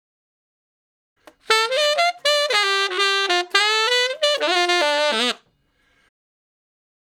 066 Ten Sax Straight (D) 33.wav